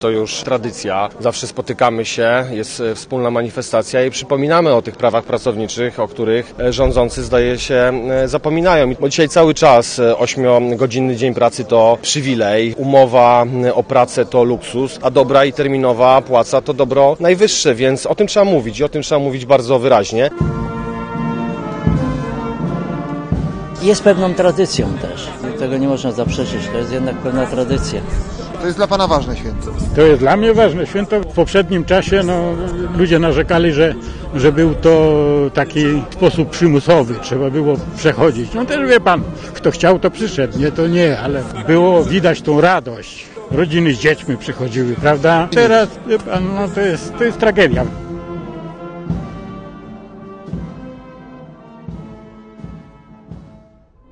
Posłuchaj relacji z pochodu: Nazwa Plik Autor Pochód 1-majowy na Piotrkowskiej audio (m4a) audio (oga) SLD organizuje dzisiajtakże pochód pierwszomajowy i piknik europejski w Zduńskiej Woli.